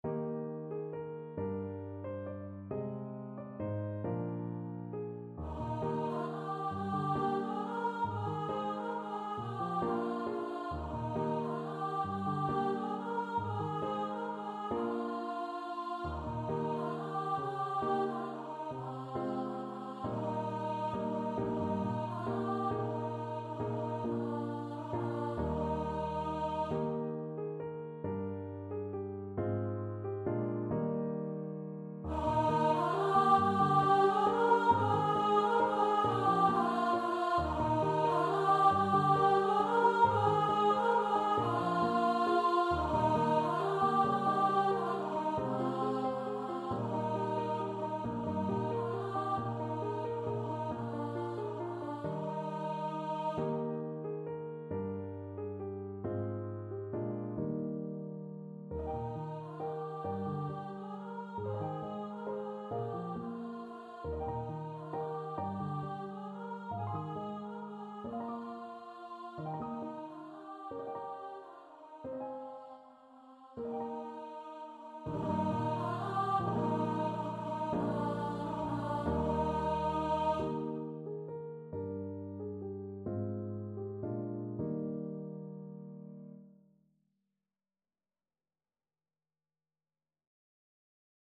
Free Sheet music for Choir (SATB)
.=45 Gently Lilting .=c.45
6/8 (View more 6/8 Music)